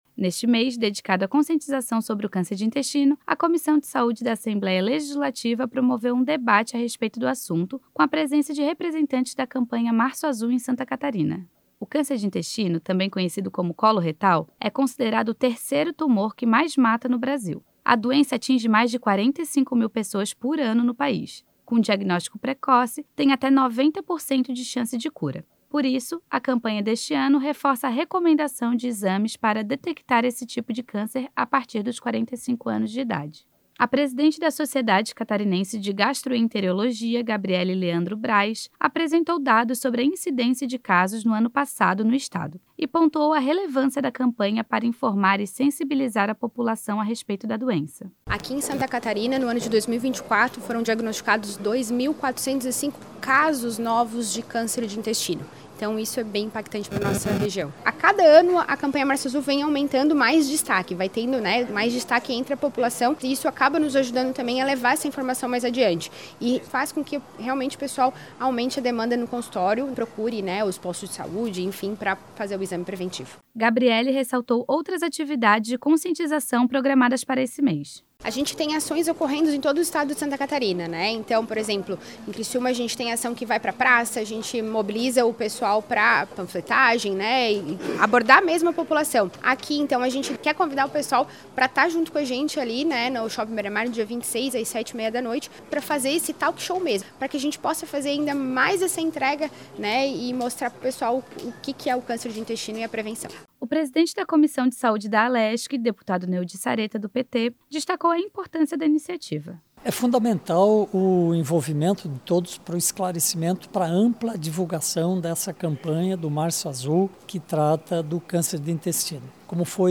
Entrevista com:
- deputado Neodi Saretta (PT), presidente da Comissão de Saúde.